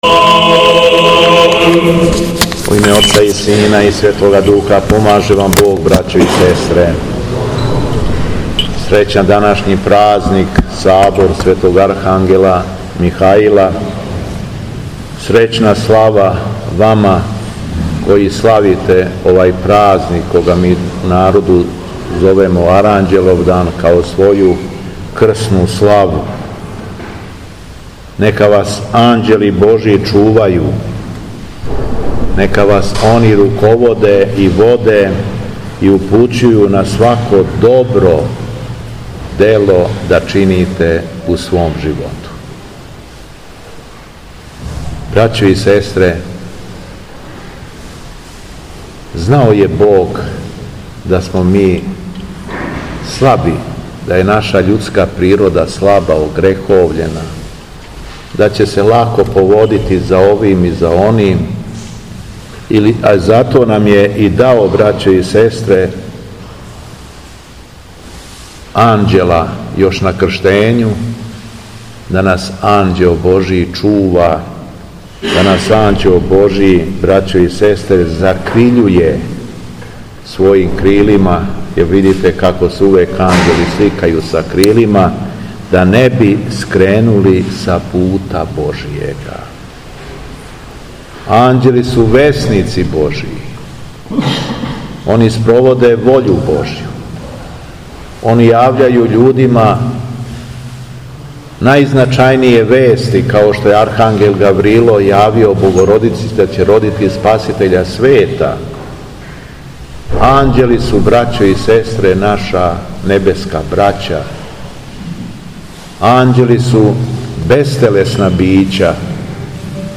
Дана 21. новембра 2024. године, на дан празника Сабора Светог архангела Михаила и свих небеских сила бестелесних, Архиепископ крагујевачки и Митрополит шумадијски Господин Јован је свештенослужио у Аранђеловцу у храму посвећеном Светим апостолима Петру и Павлу.
Беседа Његовог Високопреосвештенства Митрополита шумадијског г. Јована